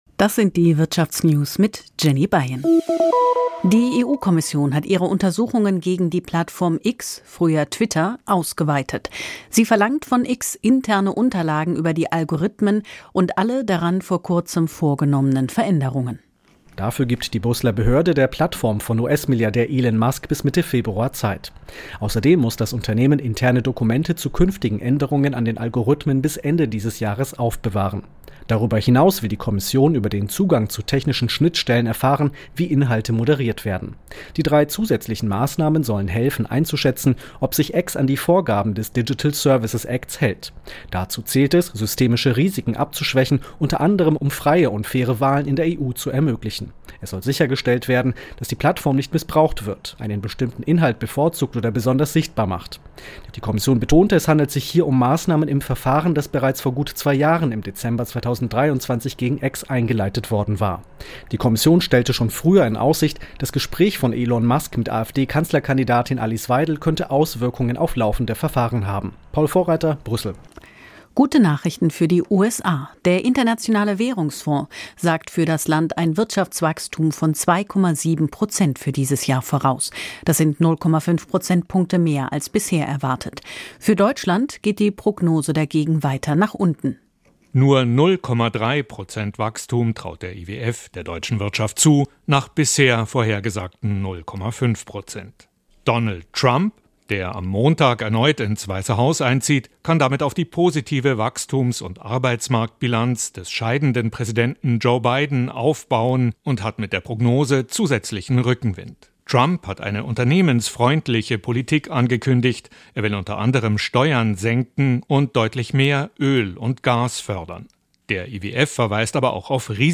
… continue reading 106 επεισόδια # Business News # Nachrichten # Südwestrundfunk # SWR Aktuell # Wirtschaft # Unternehmer # Börse # Beschäftigung # Arbeitnehmer # Arbeitgeber # Konzerne # Soziales # Gewerkschaft